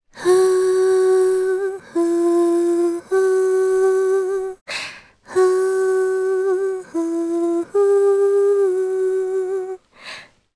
Laias-Vox_Hum_jp.wav